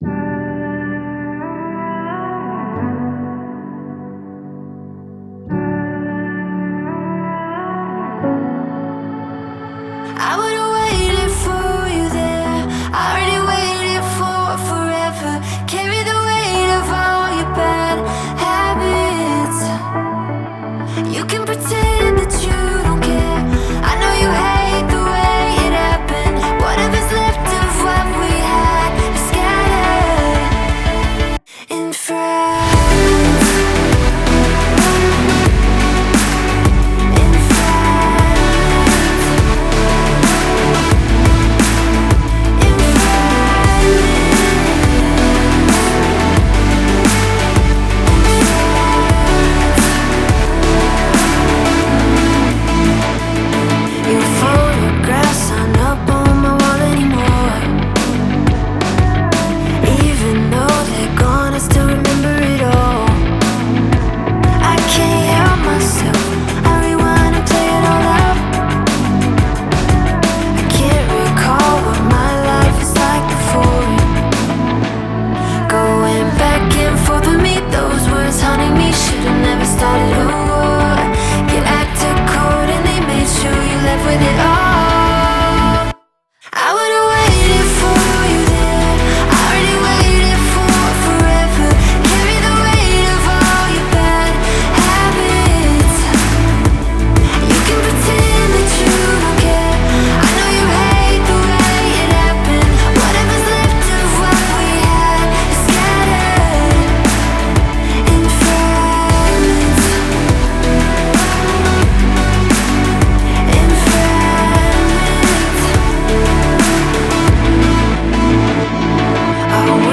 создавая атмосферу уязвимости и искренности
мощные электронные биты